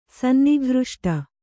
♪ sannighřṣṭa